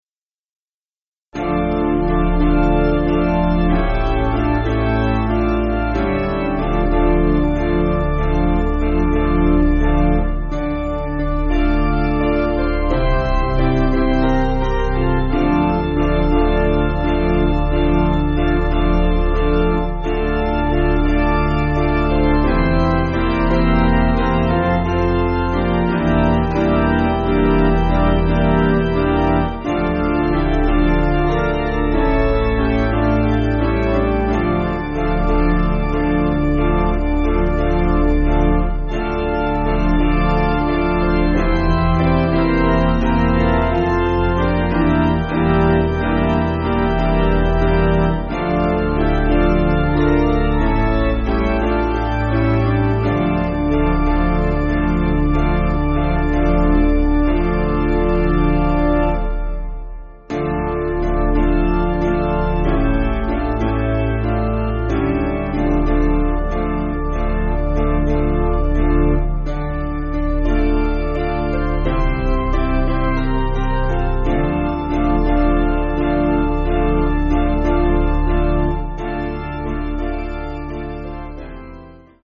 Basic Piano & Organ
(CM)   5/Dm
7/8 Time